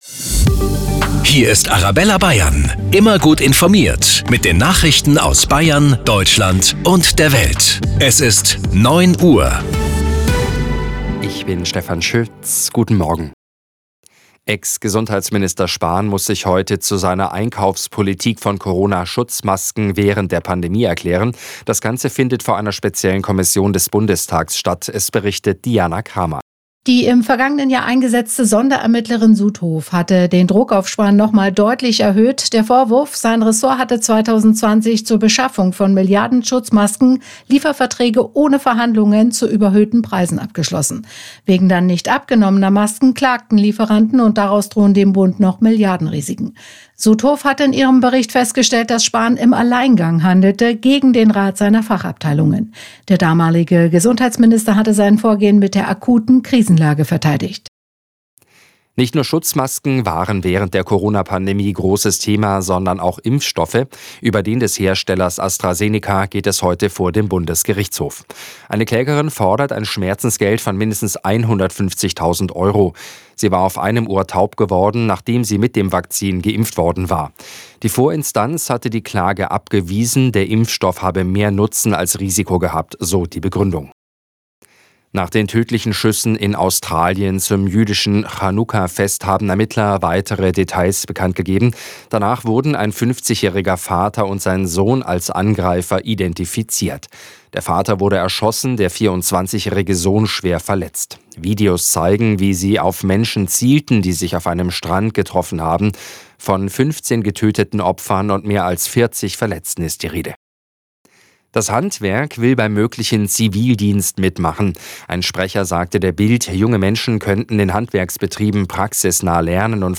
Die aktuellen Nachrichten zum Nachhören